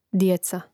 djèca djeca